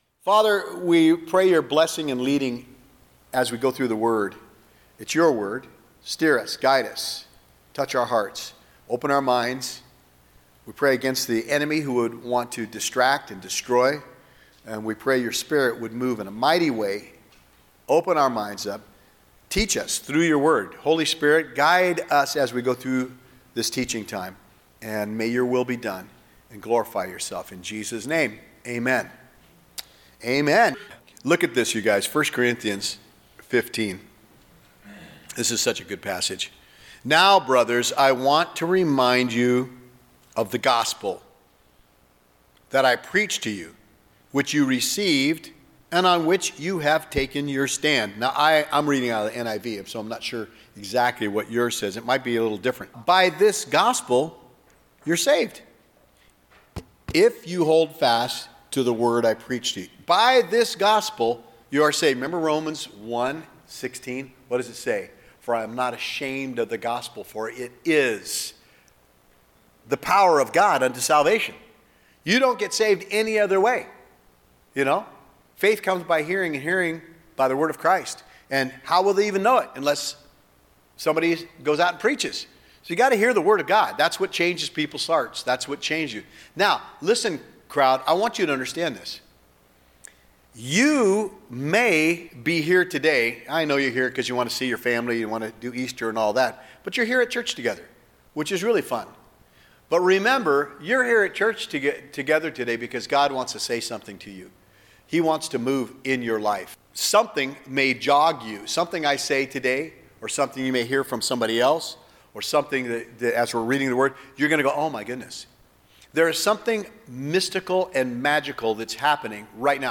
04-21-25 EASTER SERMON - 1 COR 15 THE REURRECTION AND SALVATION | The Way Church Sutherlin